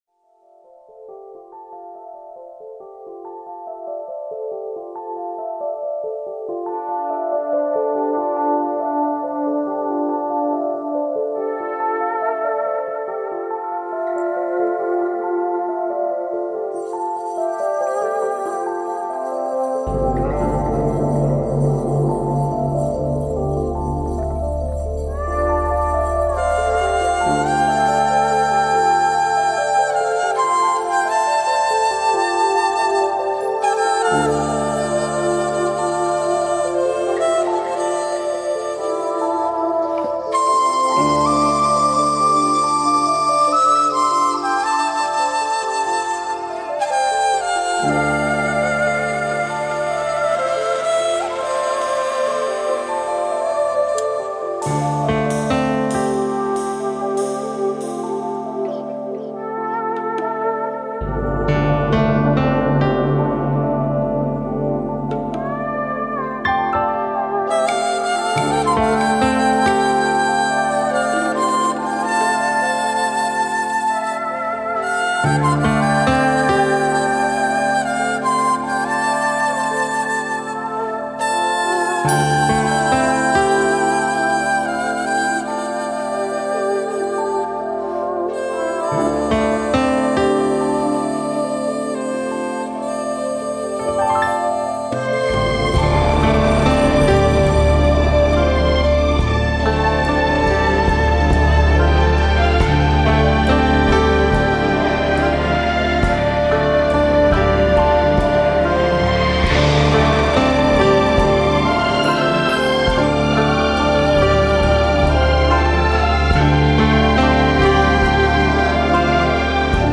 Format: Remixes included